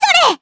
Estelle-Vox_Attack7_jp.wav